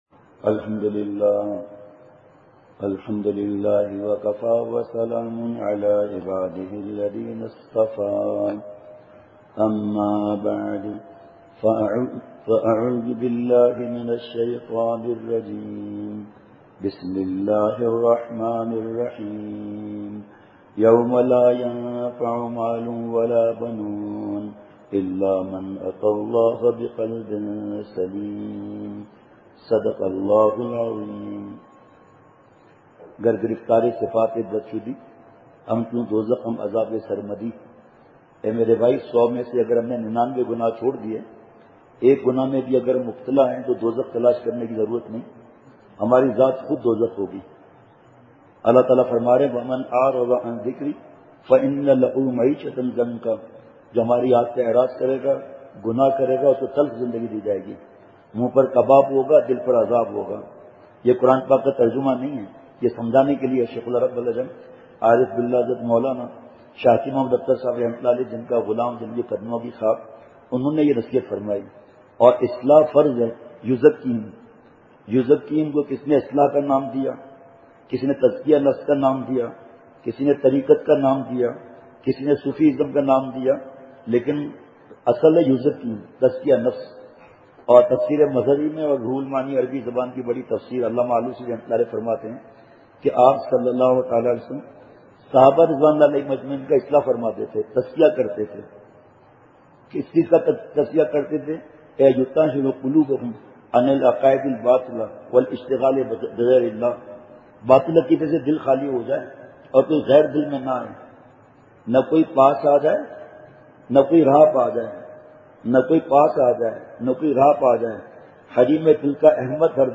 اصلاحی مجلس کی جھلکیاں